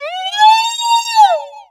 Cri de Cocotine dans Pokémon X et Y.